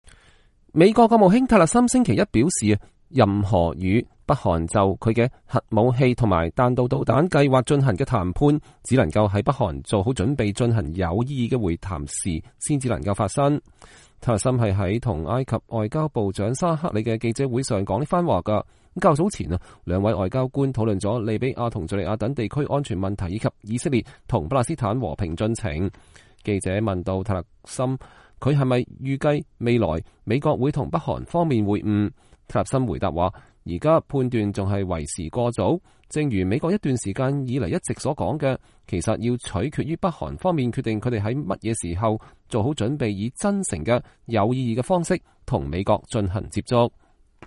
蒂勒森是在與埃及外交部長沙克里的記者會上說這翻話的。